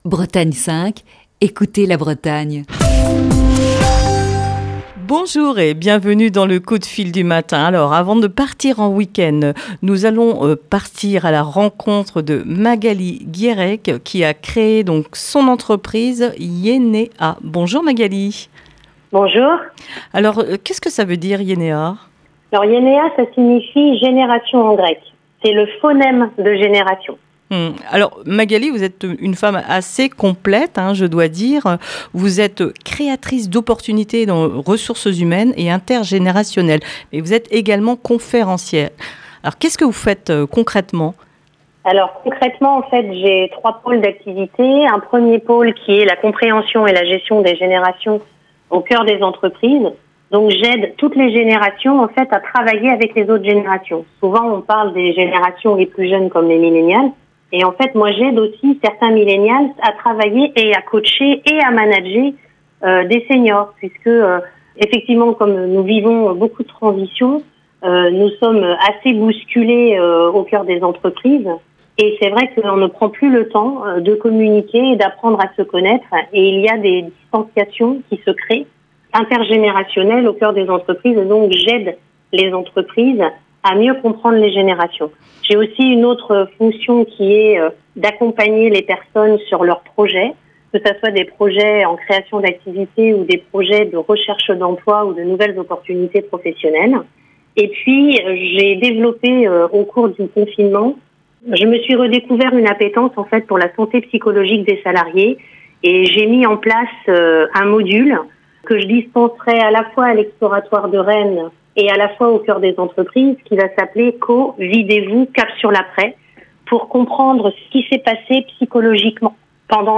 Émission du 26 juin 2020.